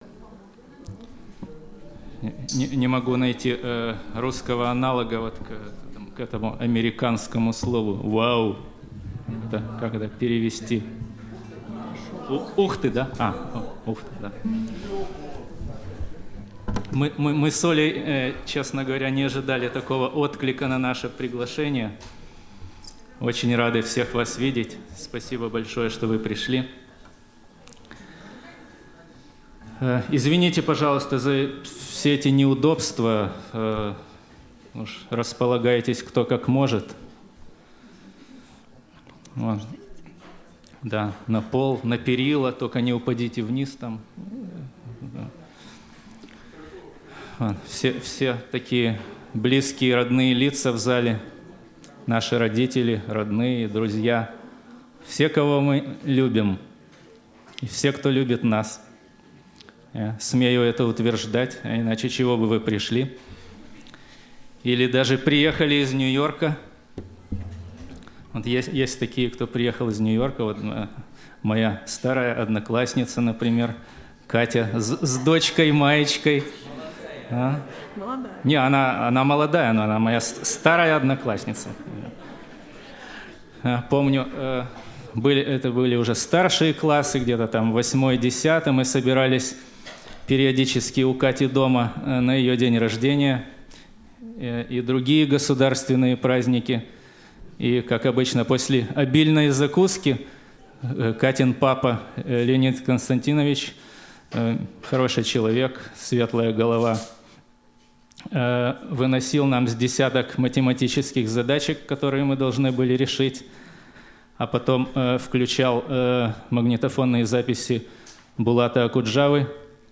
Концерт